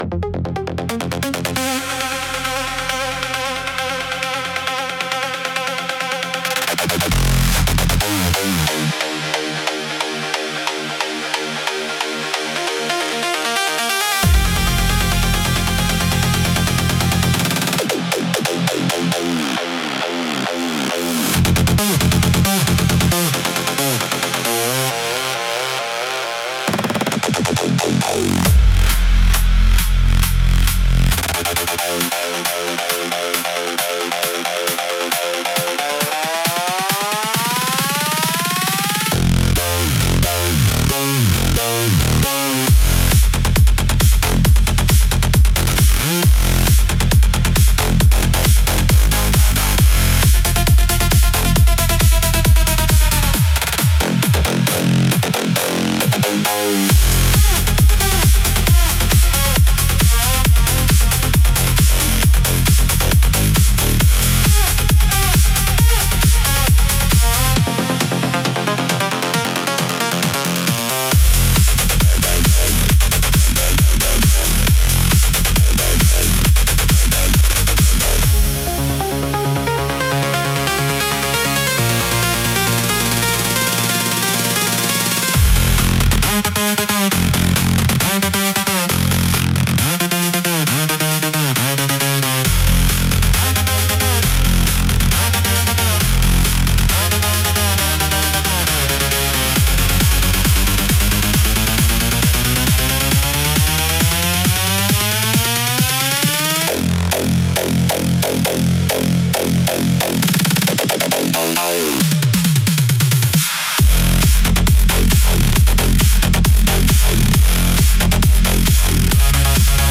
Original Electronic Music | Cyberpunk EDM
• High replay value with layered progression
• Clean low-end for powerful sound systems
• Built for forward momentum and atmospheric depth